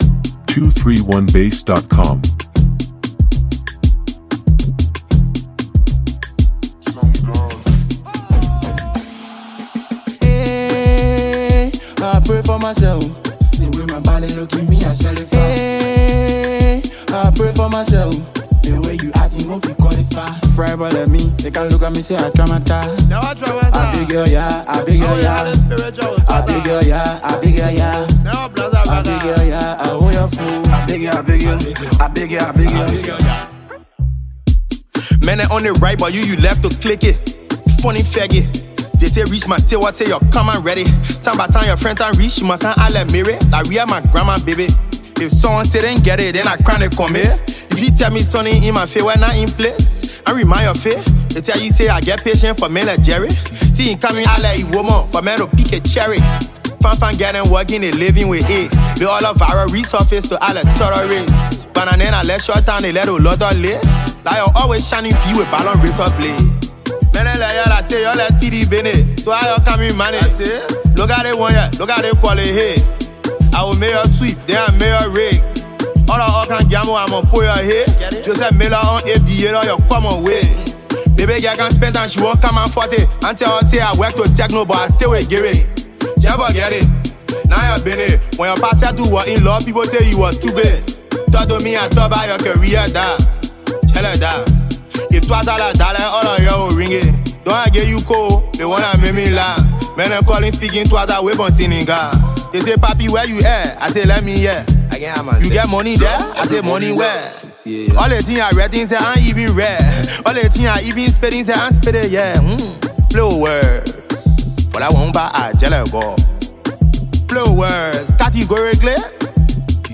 the gbwama style